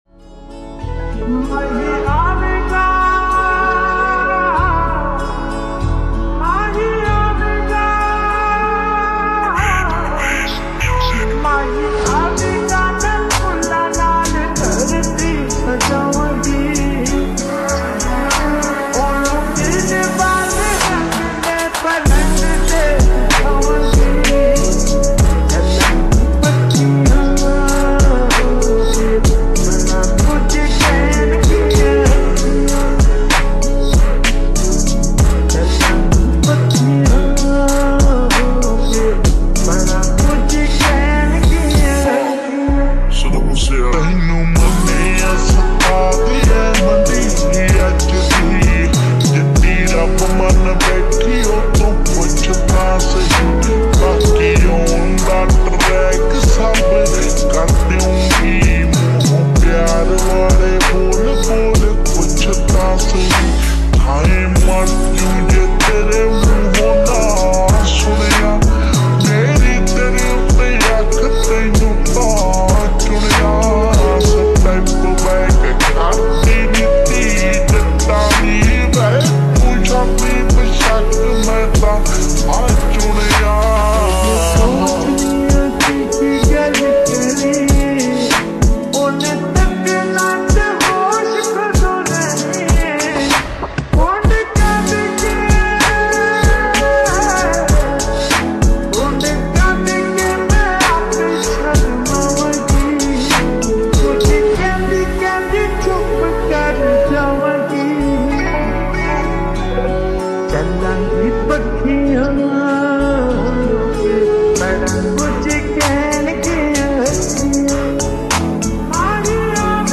slowed reverb